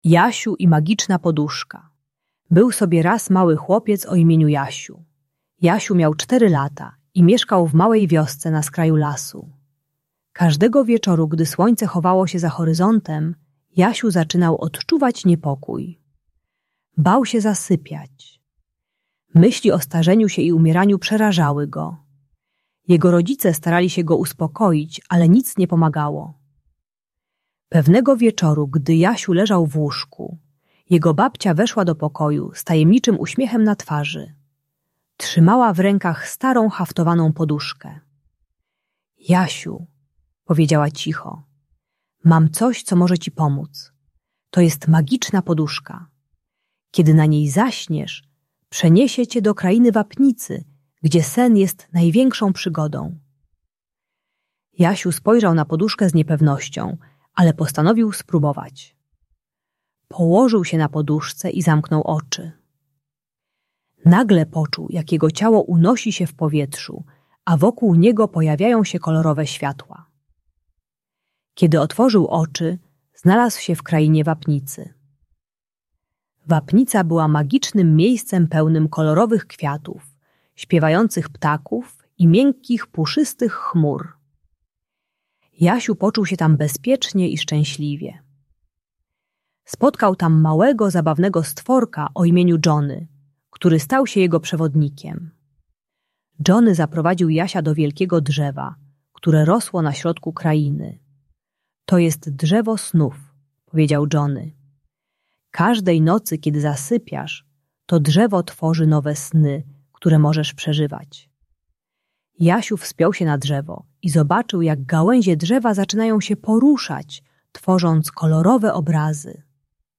Jasiu i Magiczna Poduszka - Lęk wycofanie | Audiobajka